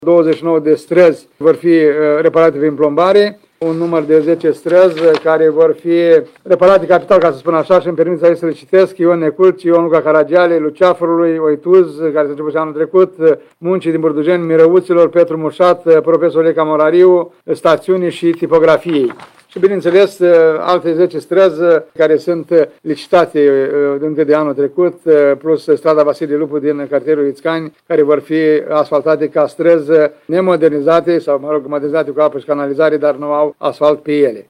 Primarul ION LUNGU a declarat că 29 străzi din municipiul reședință vor fi plombate, iar 10 străzi reparate capital.